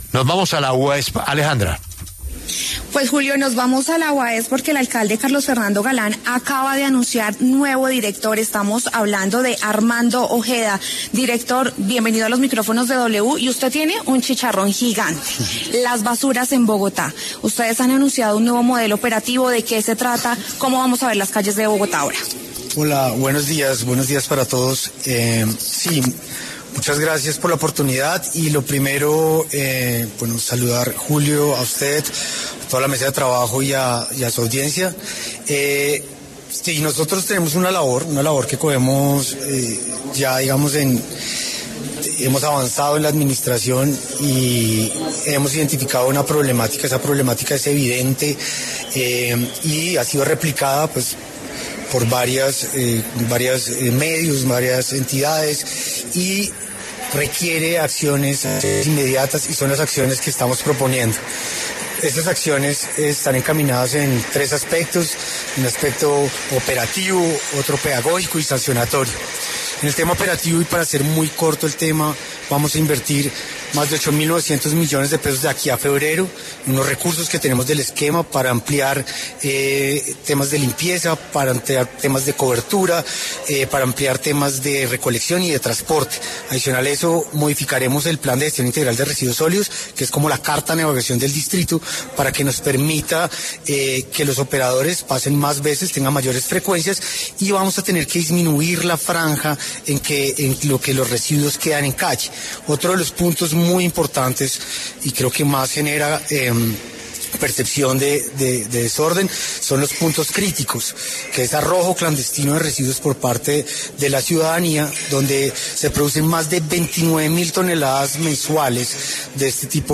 En conversación con La W, el nuevo director de la Uaesp explicó el nuevo sistema de recolección de basuras que se basa en tres puntos: operativo, pedagógico y sancionatorio.